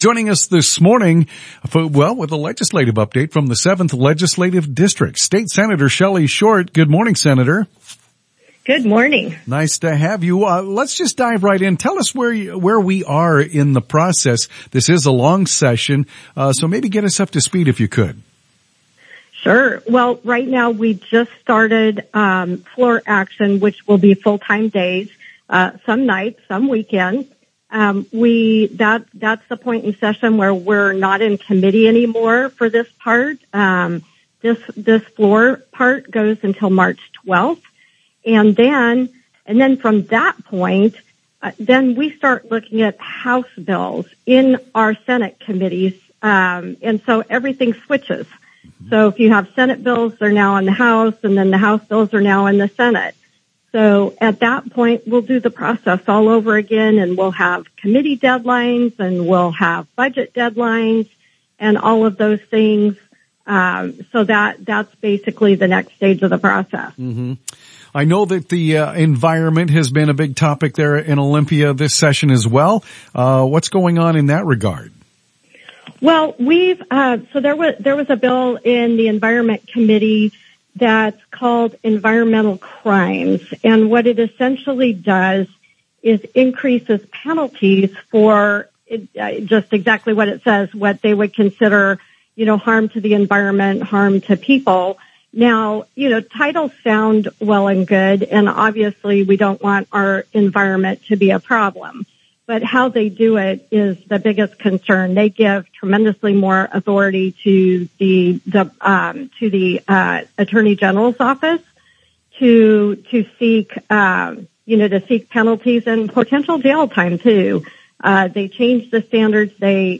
SRC On Air: Sen. Shelly Short on KOZI Radio in Chelan - Senate Republican Caucus